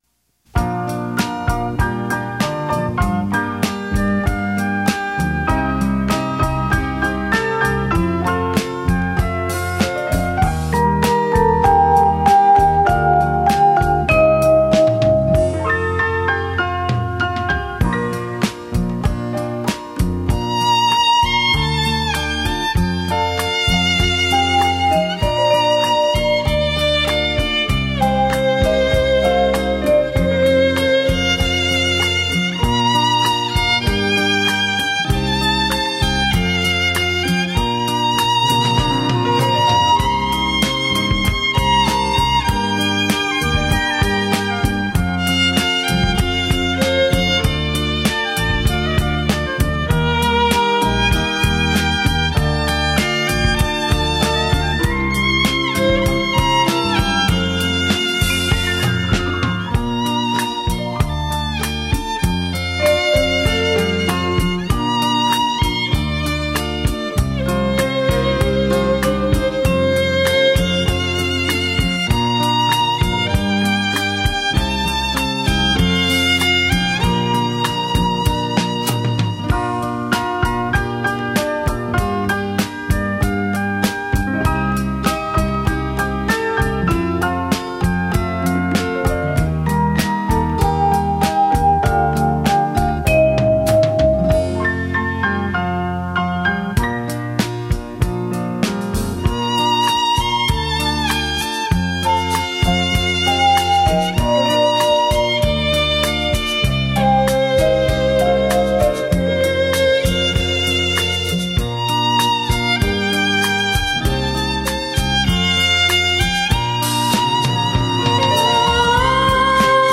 小提琴
音色迷人